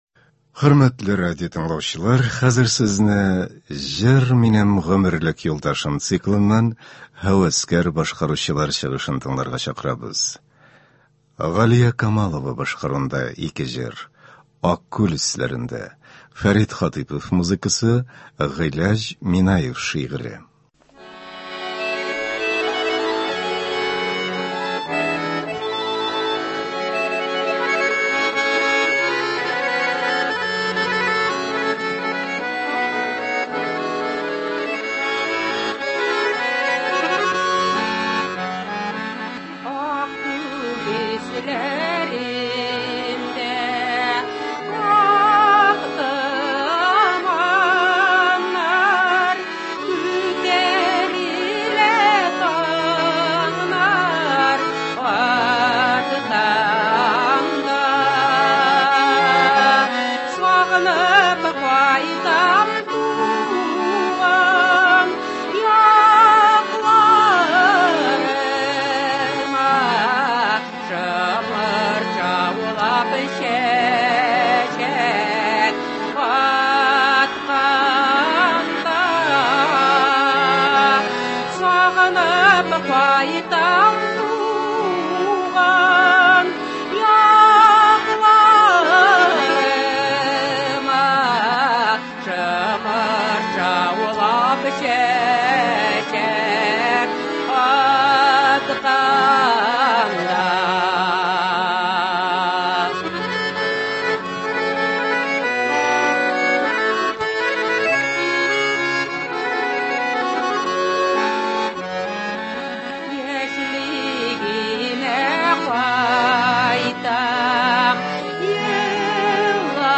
Үзешчән башкаручылар чыгышы.
Концерт (22.04.24)